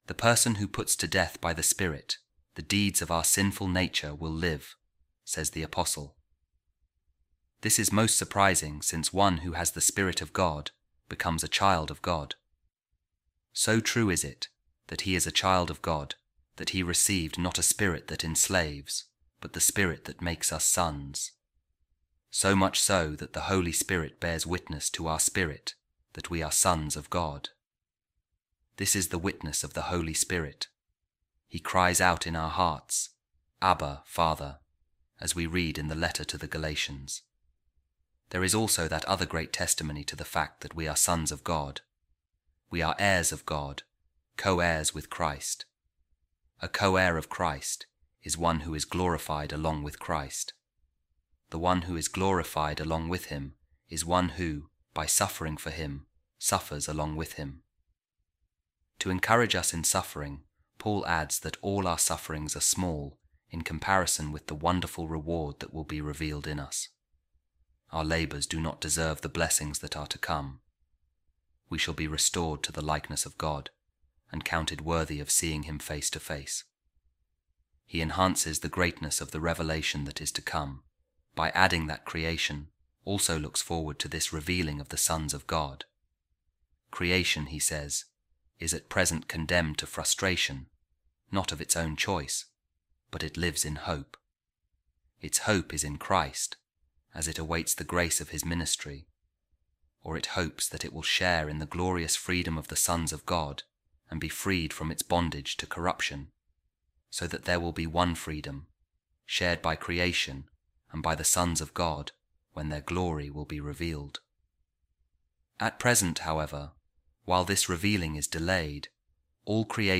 A Reading From The Letters Of Saint Ambrose | We Are Heirs Of God And Fellow Heirs With Jesus Christ